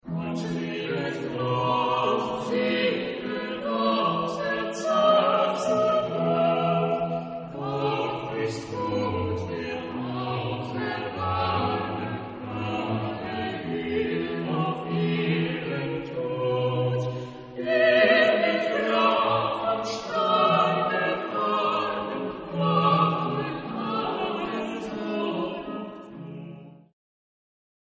Genre-Style-Forme : Sacré ; Baroque ; Choral
Type de choeur : SATB  (4 voix mixtes )